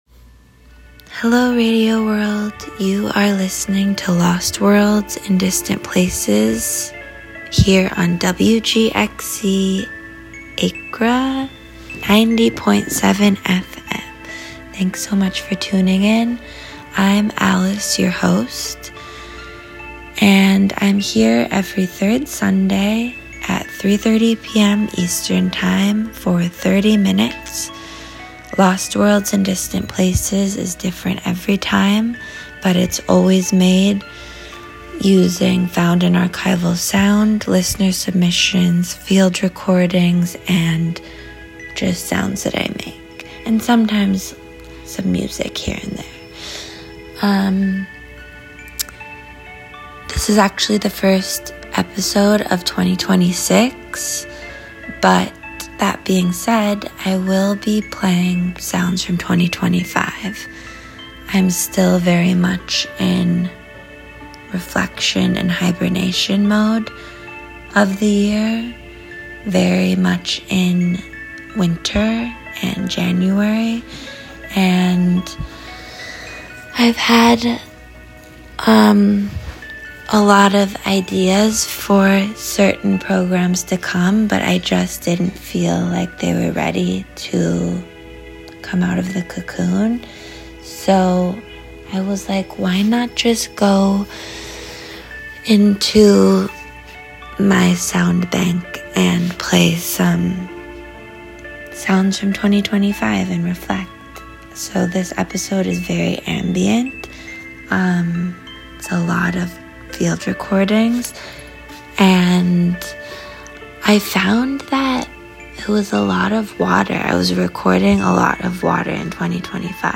Ambient sounds from 2025.